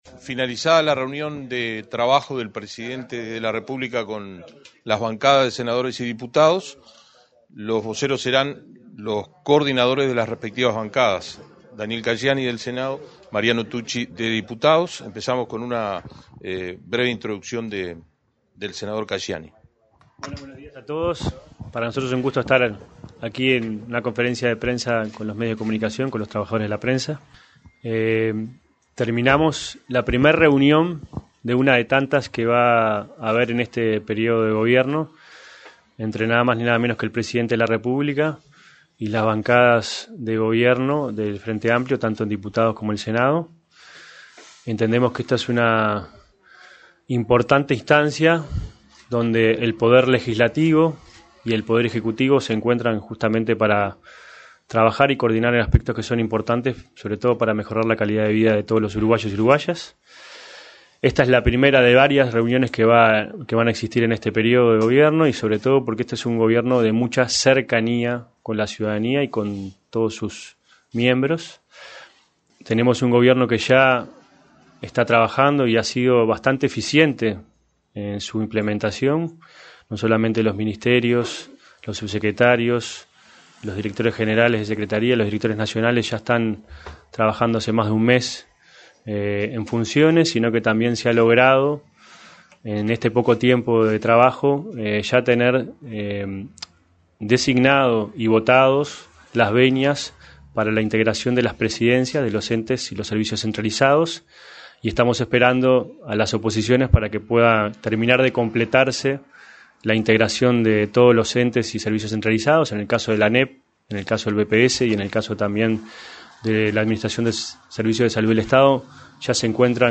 Conferencia de prensa de los coordinadores de la bancada oficialista
Conferencia de prensa de los coordinadores de la bancada oficialista 01/04/2025 Compartir Facebook X Copiar enlace WhatsApp LinkedIn Este lunes 31, los coordinadores de las Cámaras de Senadores, Daniel Caggiani, y Diputados, Mariano Tucci, realizaron una conferencia, en la sala de prensa de la residencia de Suárez y Reyes, luego de la reunión de la bancada con el presidente de la República, profesor Yamadú Orsi.